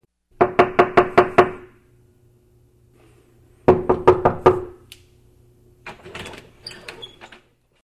05 Стук в дверь (звук).mp3 -